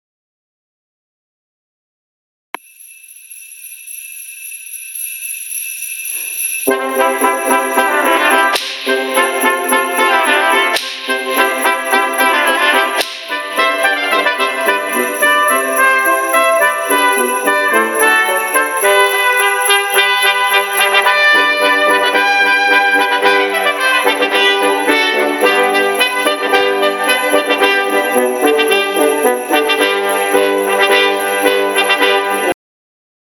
Gattung: für Blechbläserquintett
2 Trompeten, Horn, Posaune, Tuba